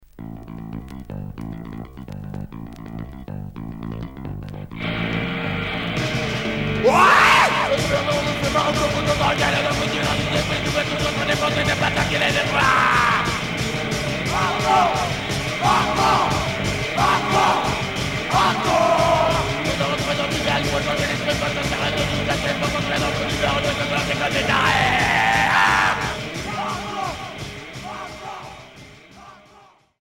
Hardcore